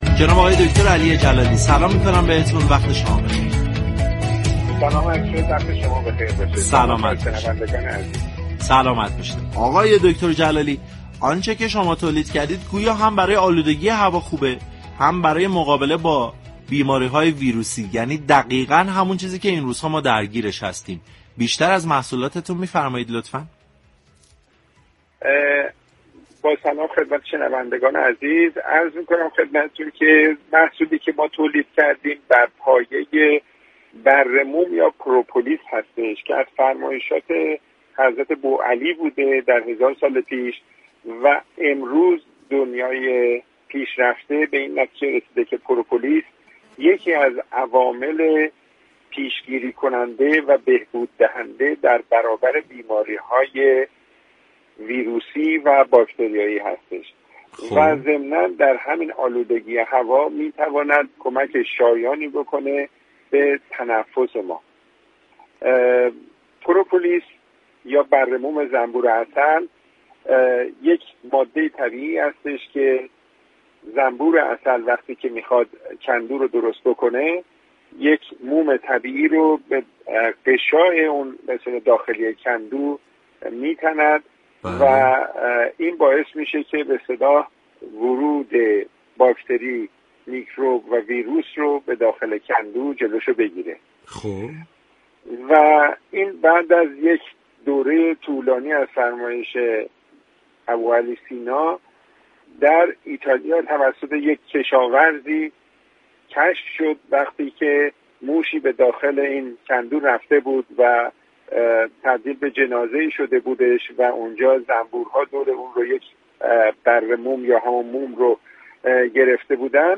گفت‌و‌گو